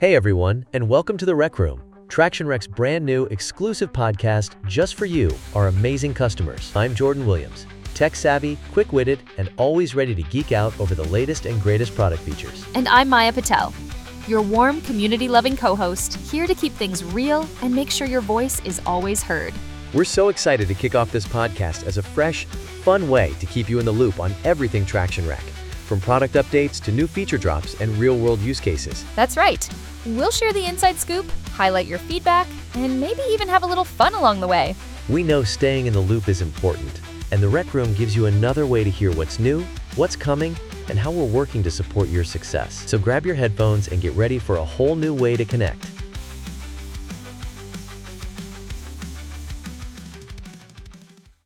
Welcome to The Rec Room — Traction Rec’s exclusive Artificial Intelligence podcast just for our amazing community!